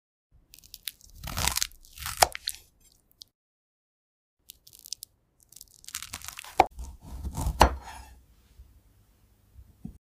Glass Berry ASMR So sound effects free download
So Mp3 Sound Effect Glass Berry ASMR - So satisfying!